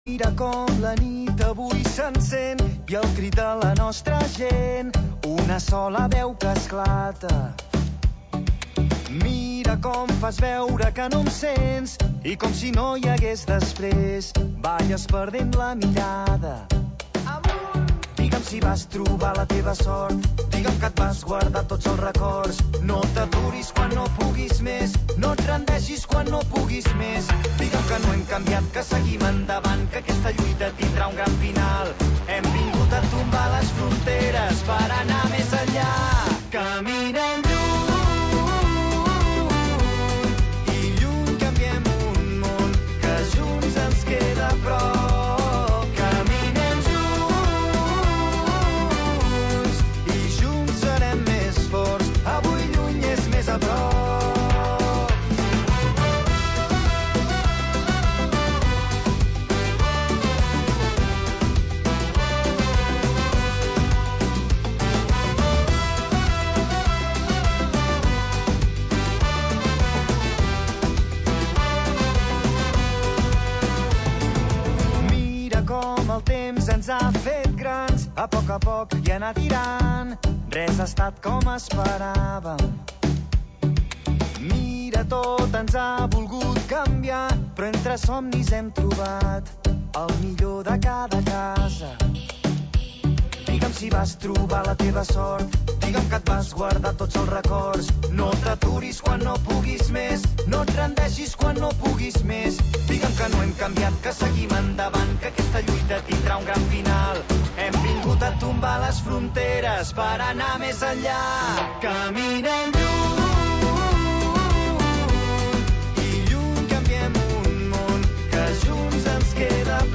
L'alcalde de l'Escala a disposició dels oients